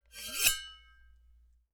Metal_82.wav